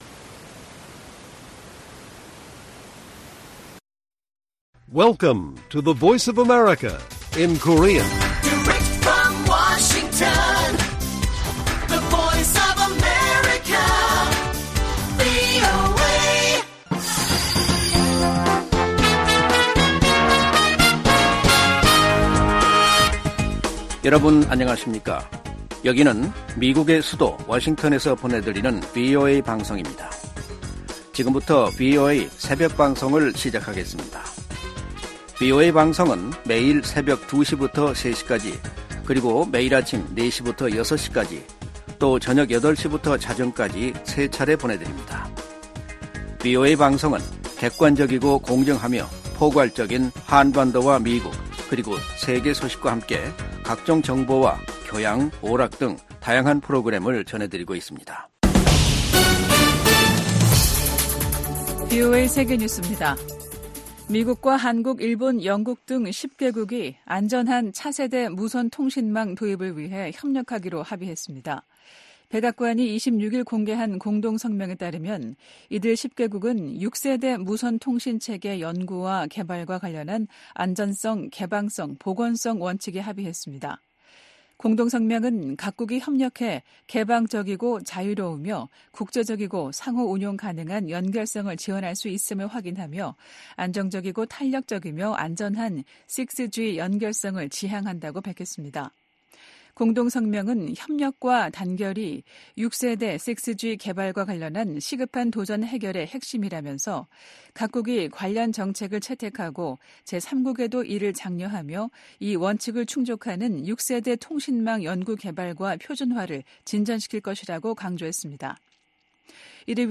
VOA 한국어 '출발 뉴스 쇼', 2024년 2월 28일 방송입니다. 제네바 유엔 군축회의 첫날 주요국들이 한목소리로 북한의 핵과 미사일 개발을 강력히 비판했습니다. 북한이 러시아에 수백만 발의 포탄을 지원했고 러시아는 대북 식량 지원에 나서 북한 내 식량 가격이 안정세를 보이고 있다고 신원식 한국 국방부 장관이 밝혔습니다. 미국 상무부는 미국산 제품이 북한 미사일에서 발견된데 우려하고, 강력 단속 의지를 밝혔습니다.